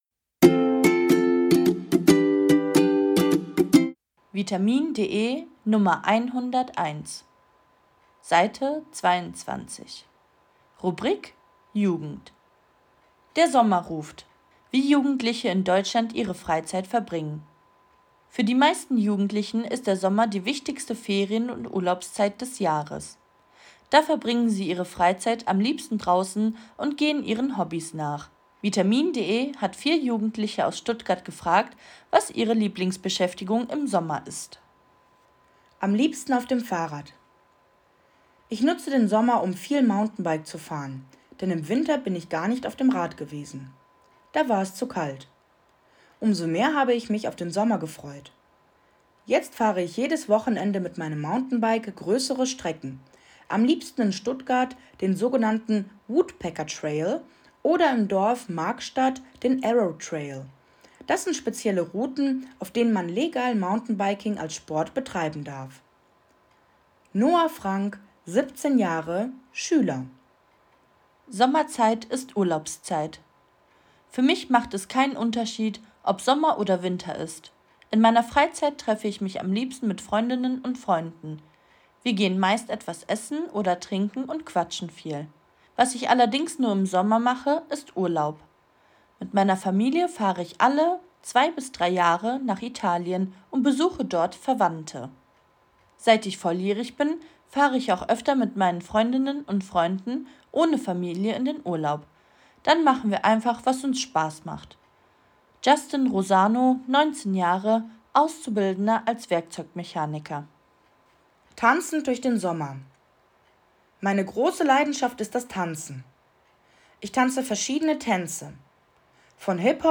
Audiodatei (Hörversion) zum Text